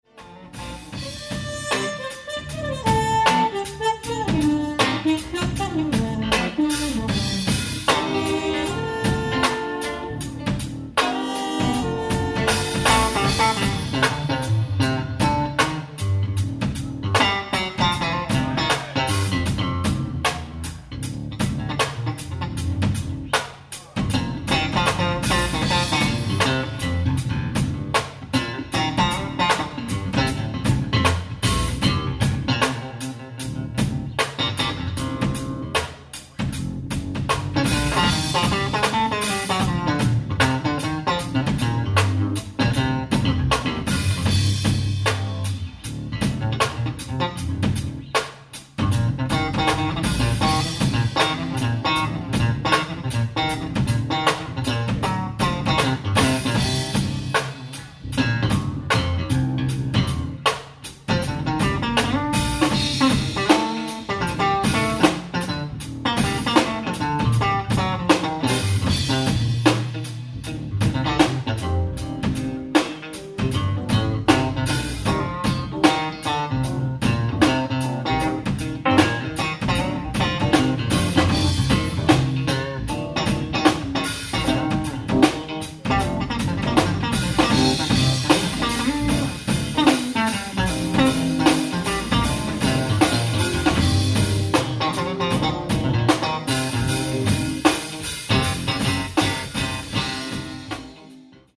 ライブ・アット・オークランド・シティー・ホール、オークランド 10/28/1999
※試聴用に実際より音質を落としています。